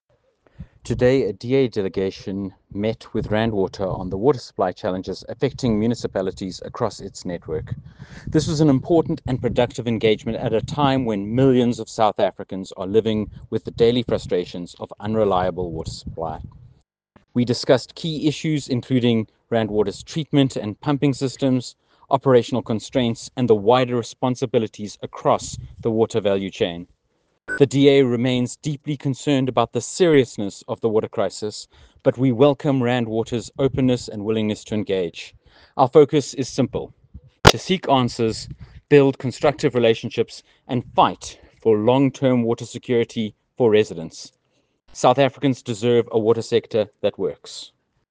soundbite by Stephen Moore MP.